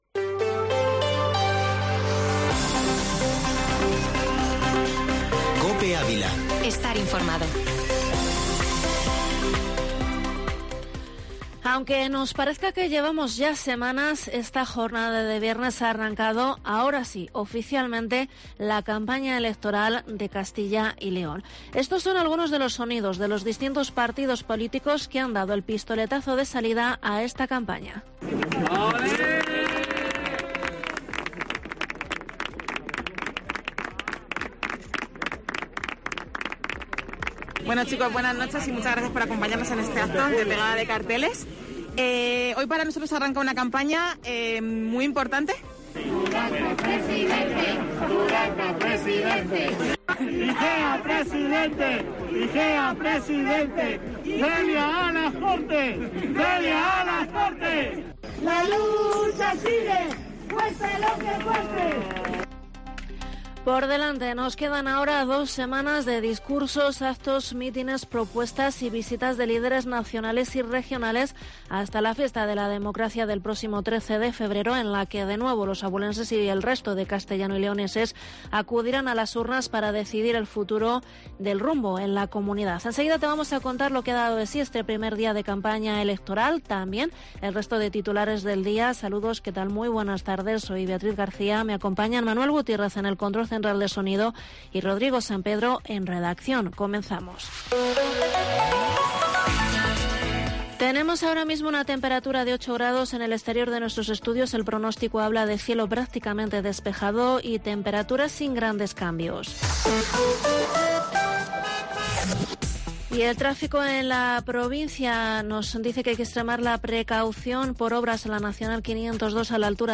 Informativo Mediodía Cope en Avila 28/1/22